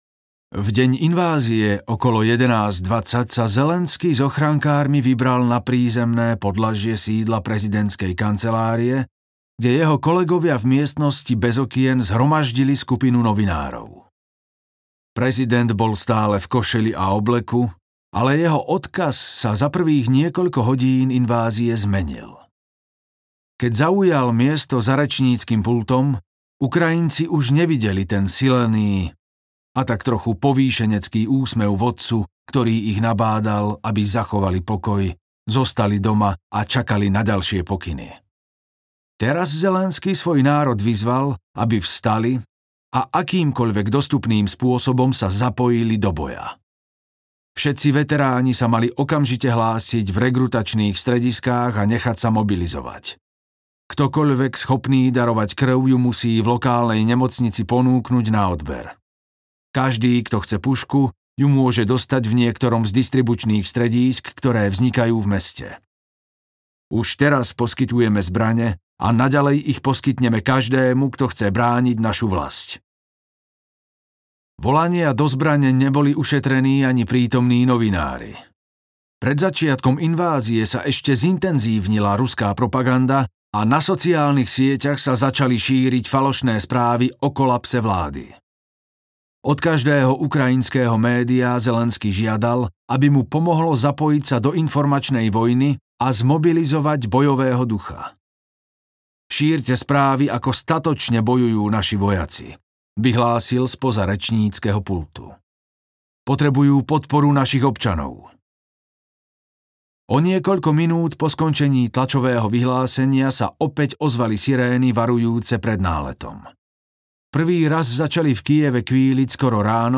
Zelenskyj audiokniha
Ukázka z knihy